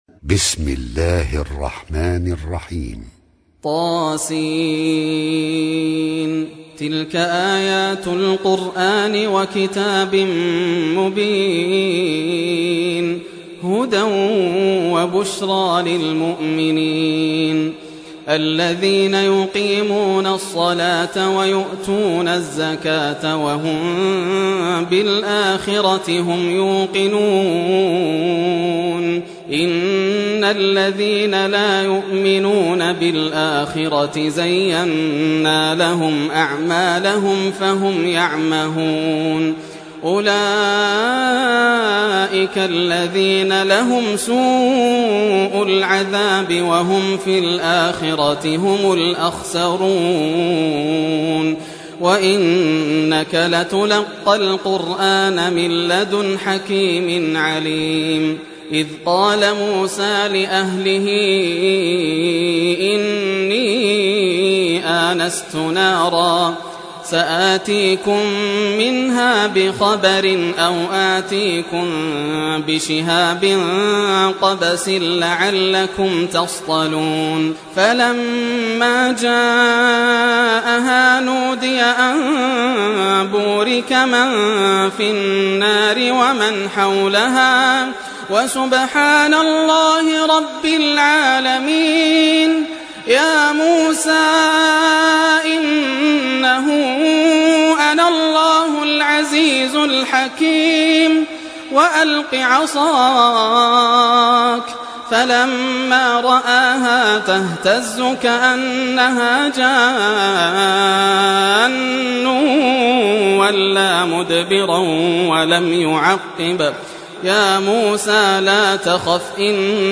Surah An-Naml Recitation by Sheikh Yasser Dosari
Surah Naml, listen or play online mp3 tilawat / recitation in the beautiful voice of Sheikh Yasser Al Dosari.